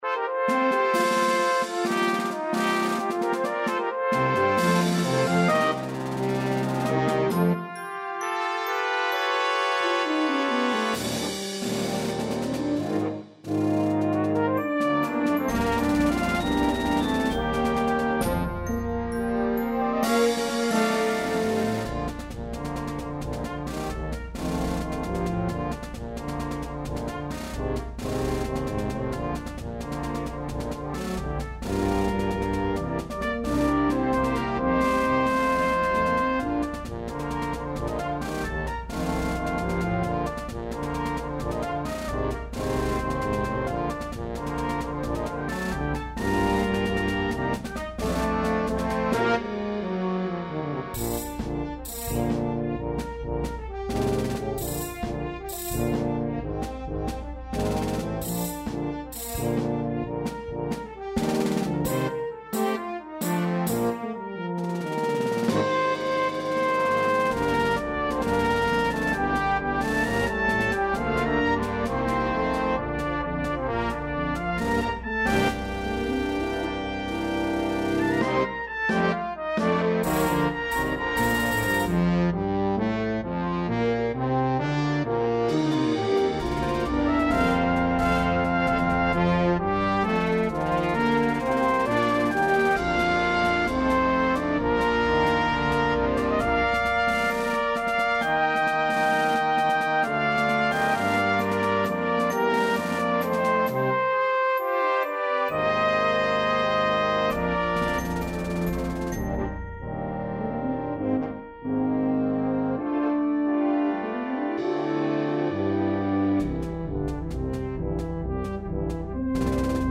With a typical militaristic flair